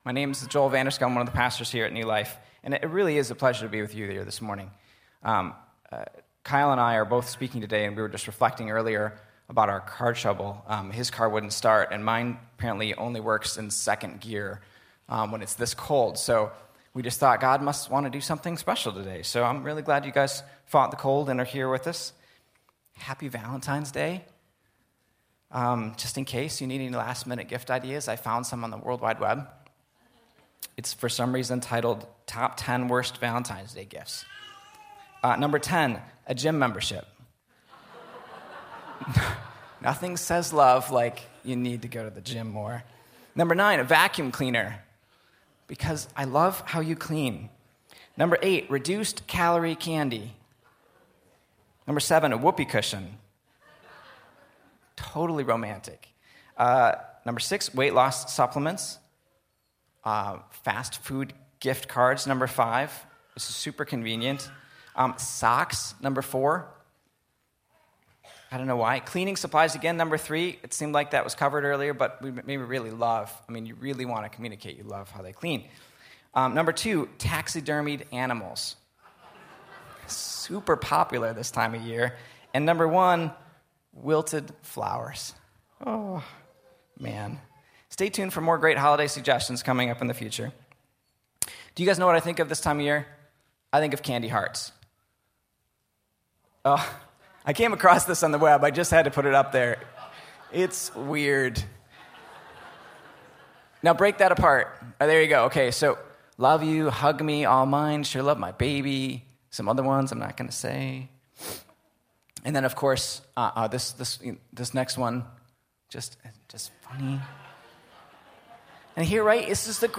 Audio Sermon Save Audio Save PDF https